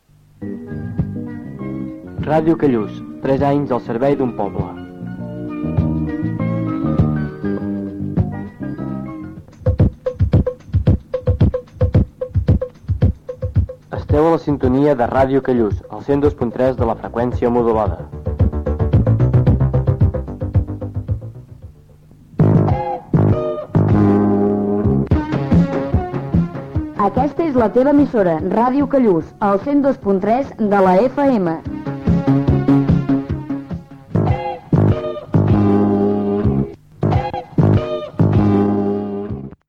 Indicatius